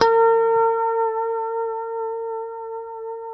B4 PICKHRM2A.wav